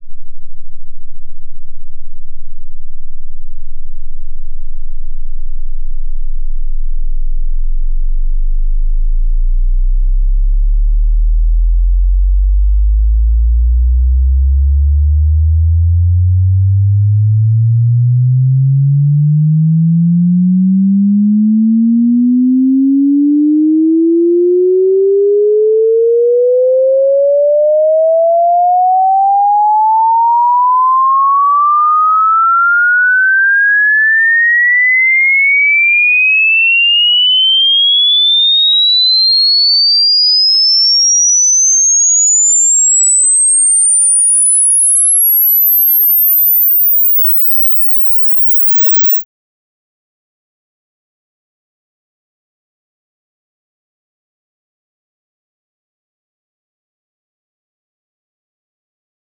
Sweep.wav